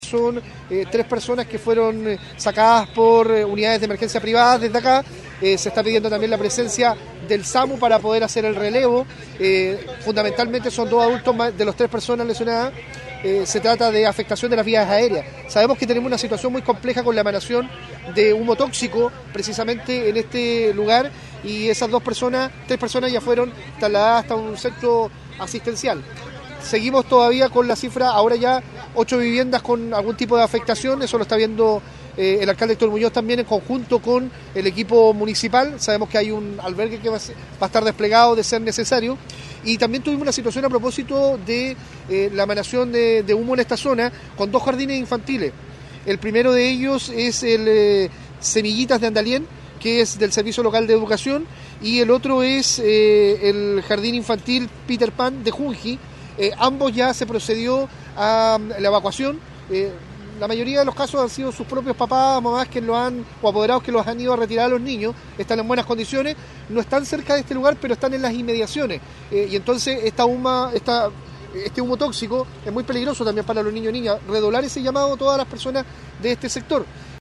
El delegado presidencial regional, Eduardo Pacheco, llegó hasta el sitio del suceso e informó que tres personas fueron trasladadas por complicaciones de salud, además de llamar a la ciudadanía a tomar las precauciones necesarias ante el impacto del humo tóxico en la ciudad.